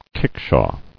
[kick·shaw]